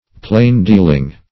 Meaning of plain-dealing. plain-dealing synonyms, pronunciation, spelling and more from Free Dictionary.
plain-dealing.mp3